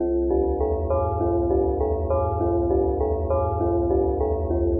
吓人的钟声为硬陷阱
标签： 200 bpm Trap Loops Bells Loops 827.05 KB wav Key : Unknown
声道立体声